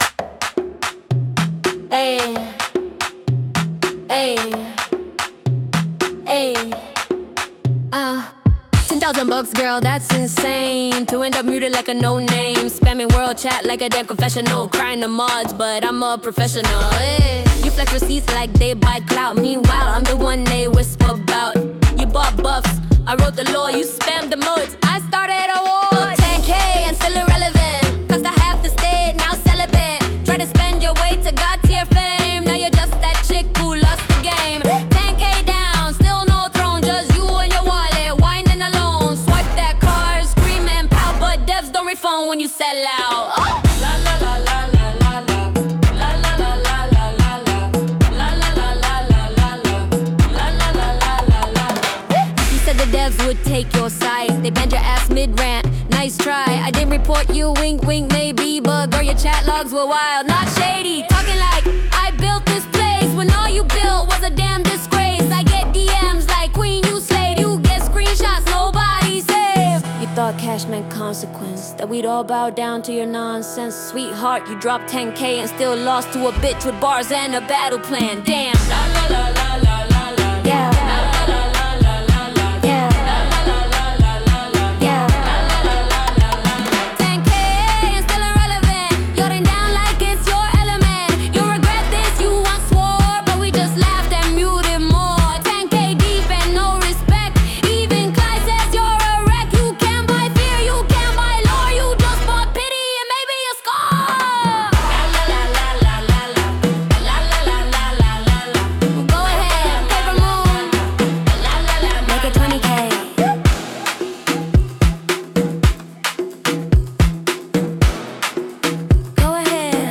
Volume normalized for web playback.